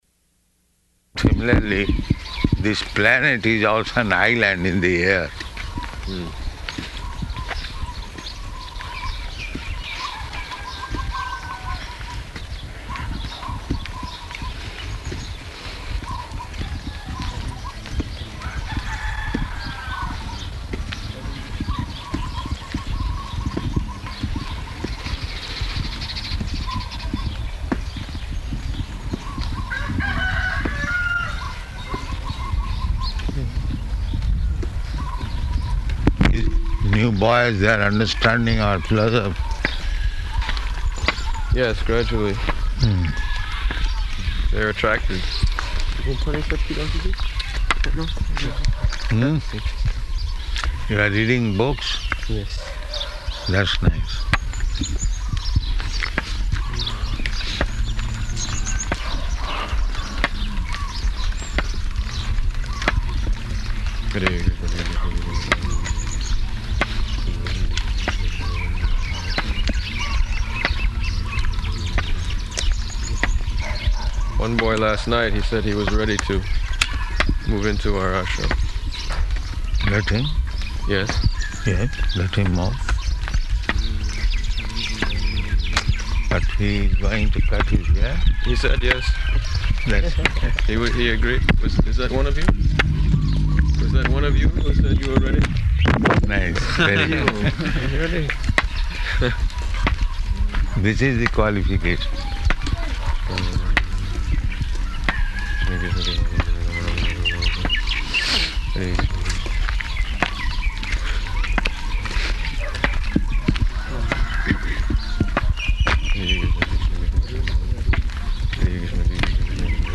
Morning Walk --:-- --:-- Type: Walk Dated: October 5th 1975 Location: Mauritius Audio file: 751005MW.MAU.mp3 Prabhupāda: ...similarly, this planet is also an island in the air.